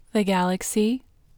WHOLENESS English Female 13